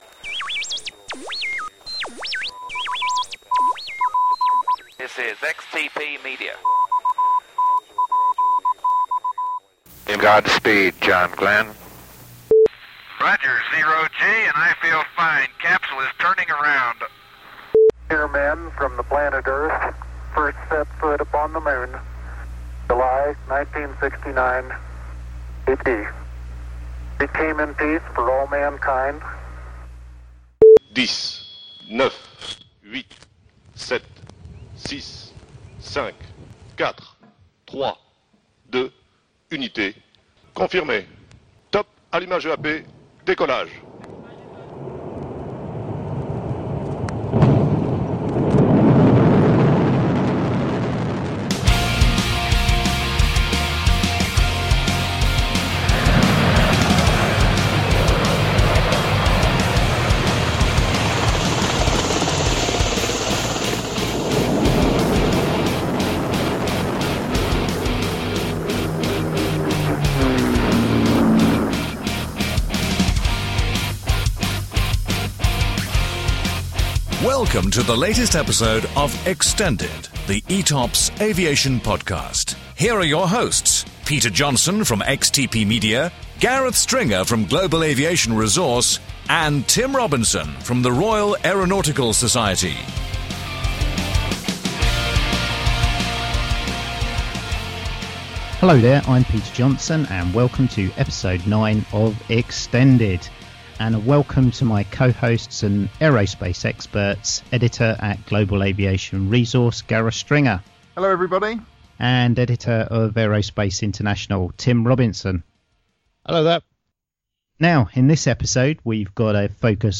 In our inaugural episode covering the subject of space, we are blessed with a plethora of amazing guests from the sector.